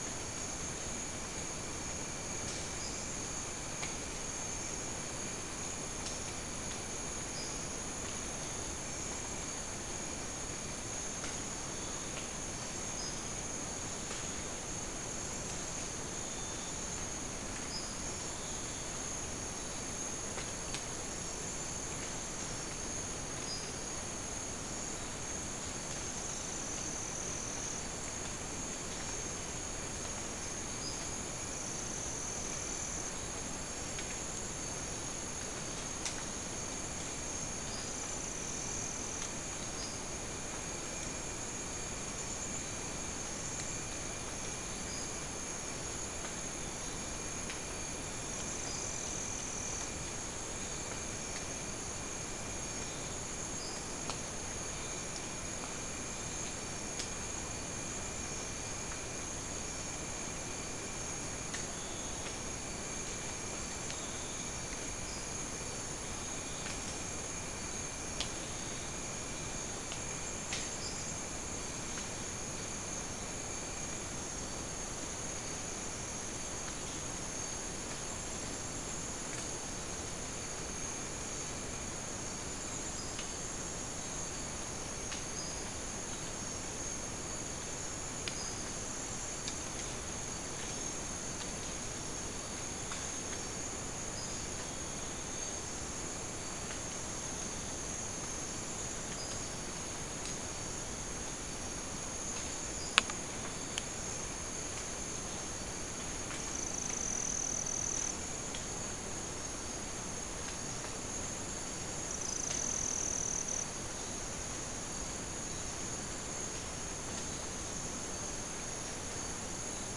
Non-specimen recording: Soundscape Recording Location: South America: Guyana: Rock Landing: 1
Recorder: SM3